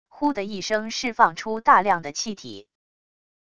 呼的一声释放出大量的气体wav音频